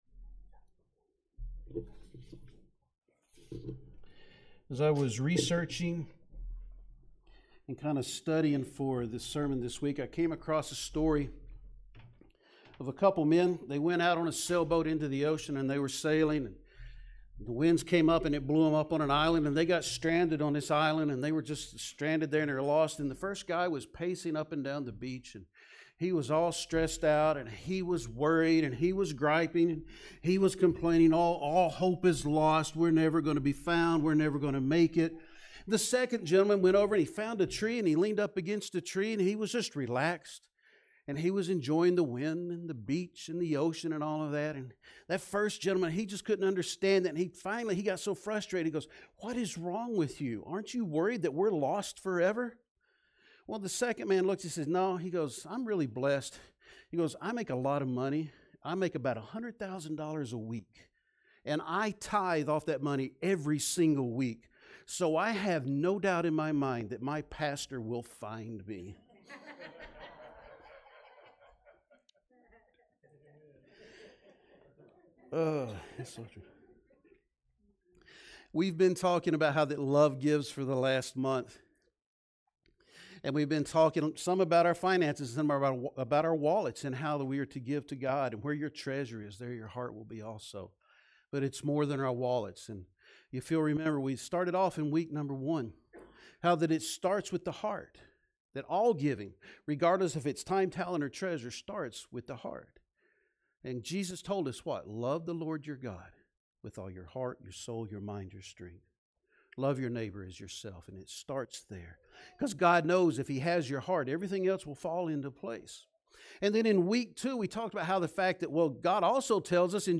Sermons | Summitville First Baptist Church